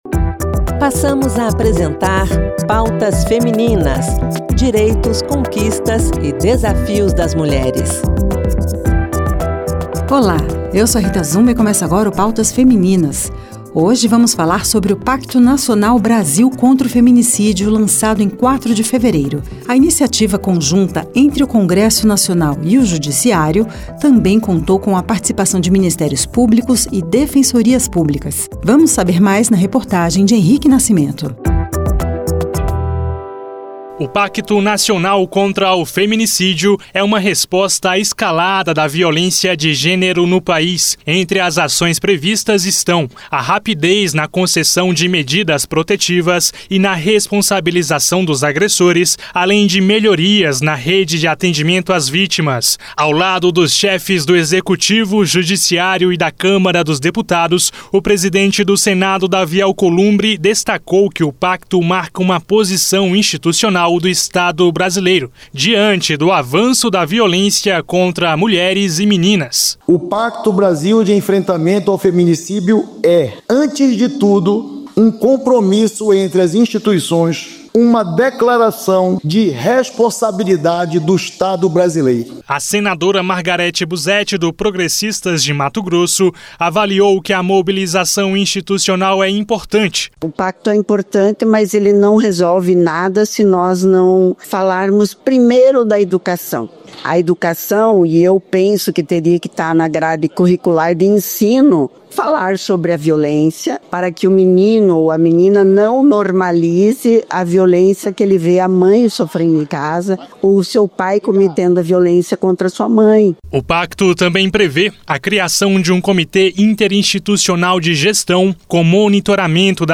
A edição traz ainda entrevista com a senadora Daniela Ribeiro, integrante do comitê gestor, que defende a integração entre as instituições e a garantia de recursos para que as políticas públicas sejam efetivas.